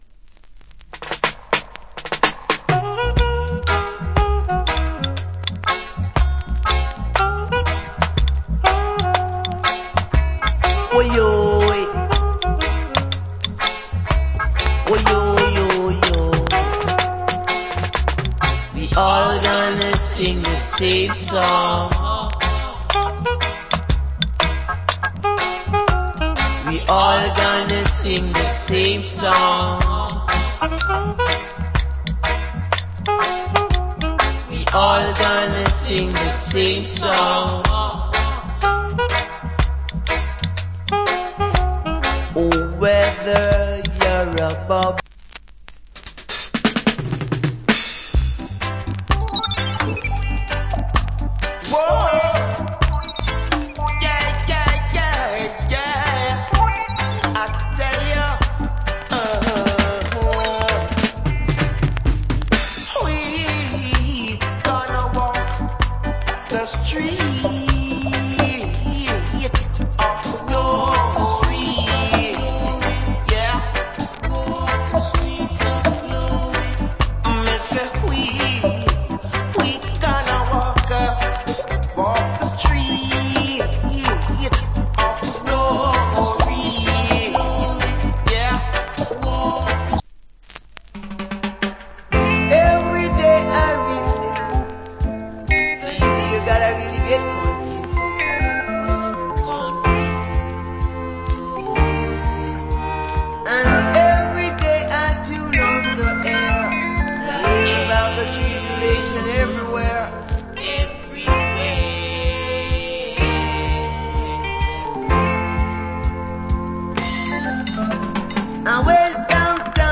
Nice Roots.